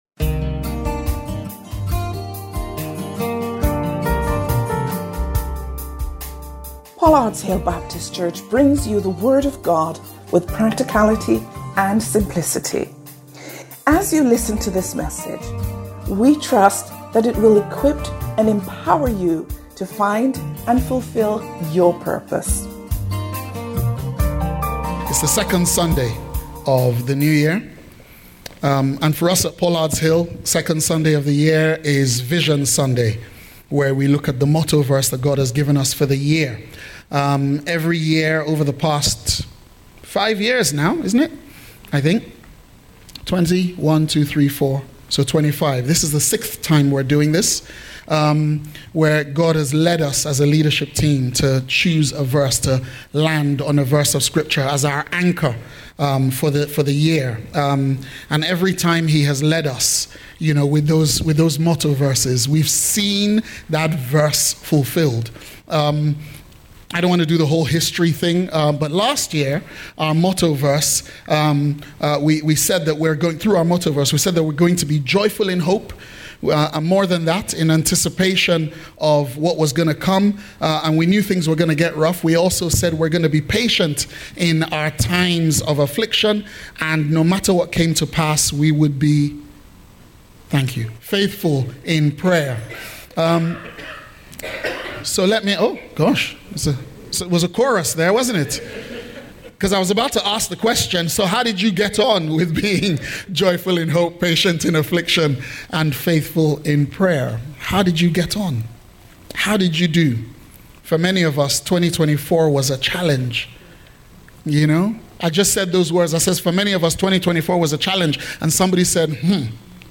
Watch this service and more on our YouTube channel – CLICK HERE